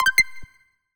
Universal UI SFX / Basic Menu Navigation
Menu_Navigation01_Options.wav